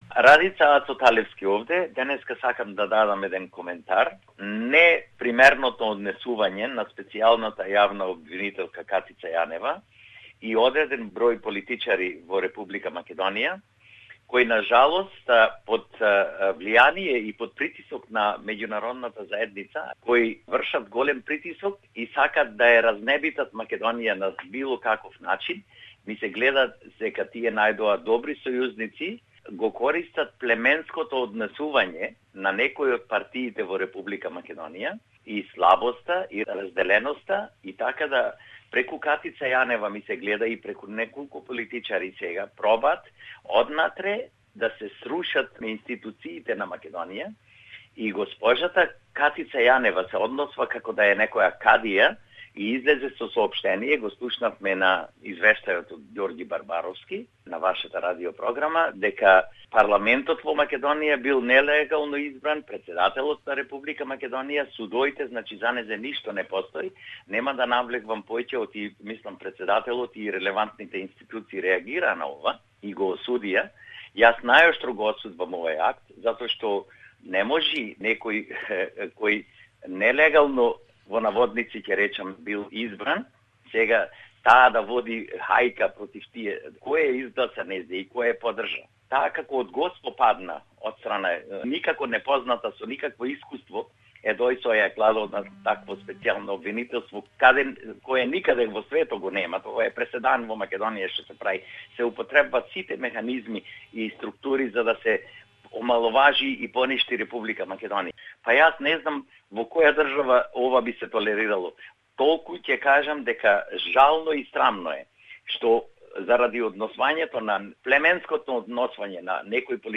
Voxpop: " Macedonian Special Public Prosecutor- precedent not found anywhere in the world"